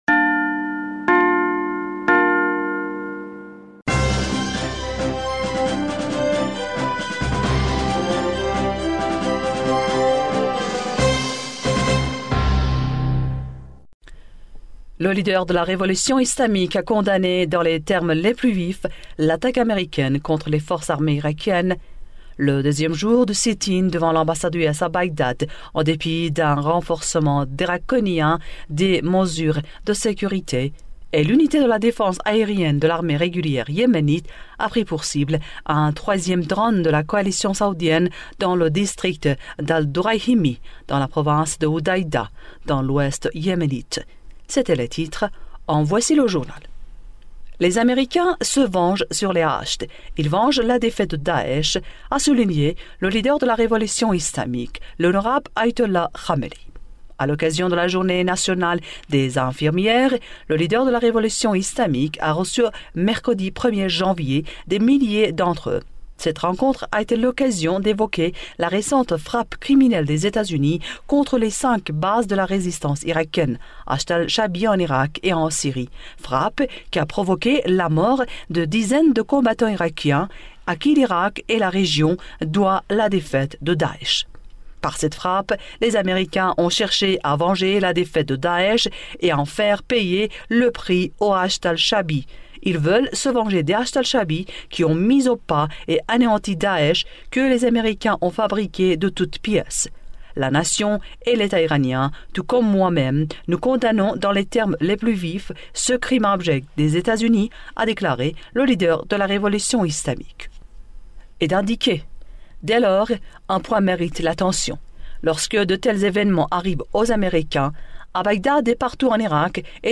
Bulletin d'information du premier janvier 2020